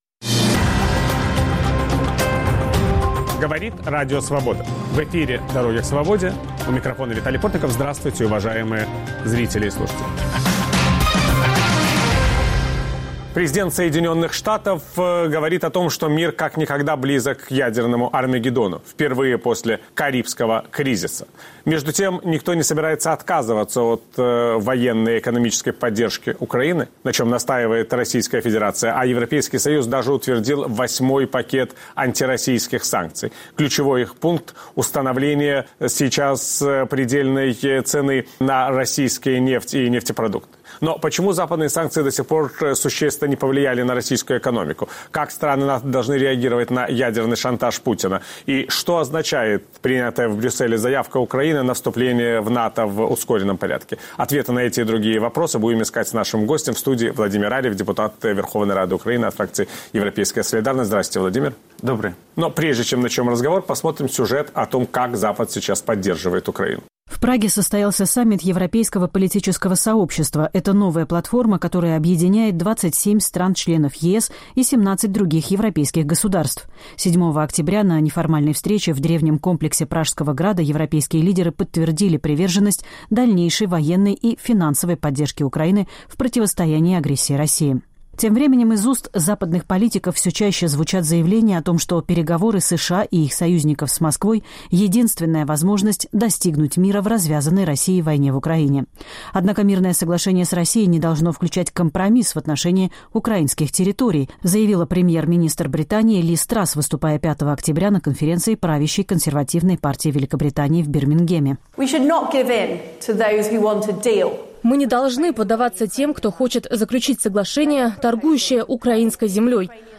Собеседник Виталия Портникова - депутат Верховной Рады Украины Владимир Арьев.